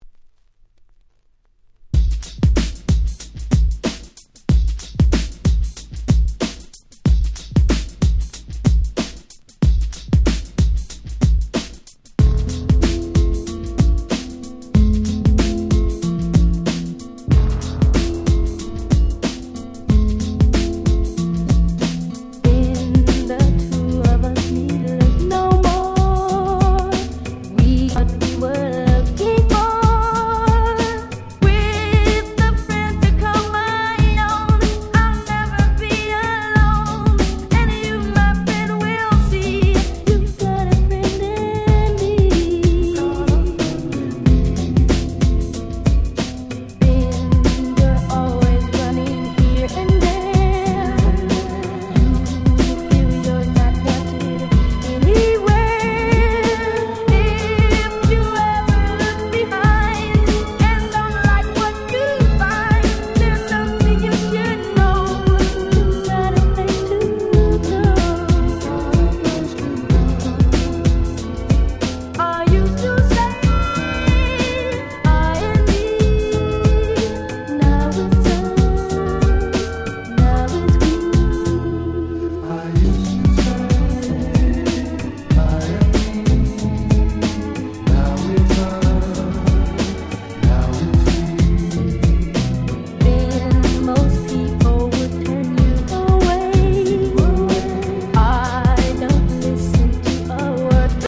90's R&B